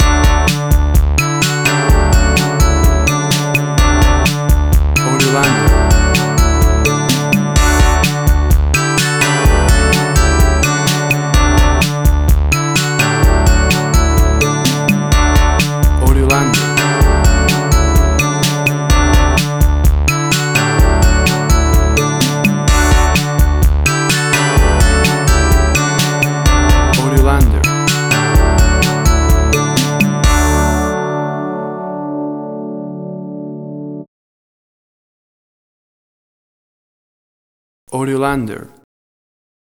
A cool explosion of classic 80s synth music!
WAV Sample Rate: 16-Bit stereo, 44.1 kHz
Tempo (BPM): 128